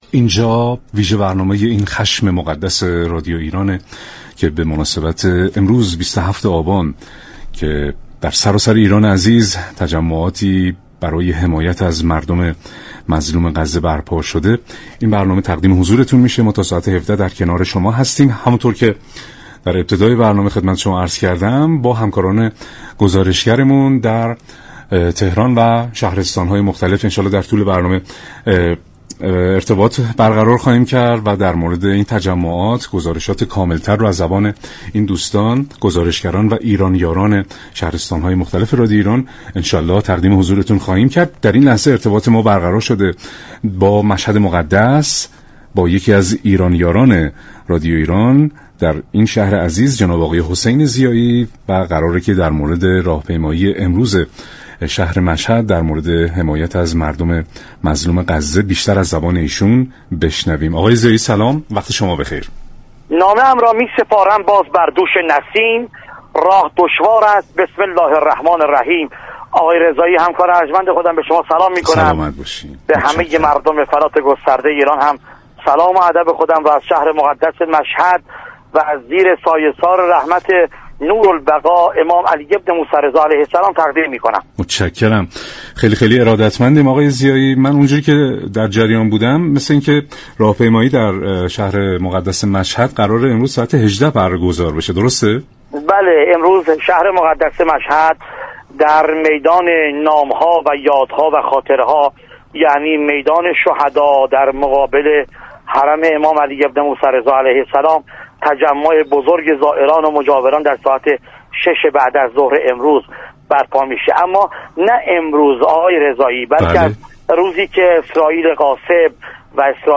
ایرانیاران از شهرهای مختلف ایران از حضور گسترده مردم جهت حمایت از مردم فلسطین گزارش می دهند.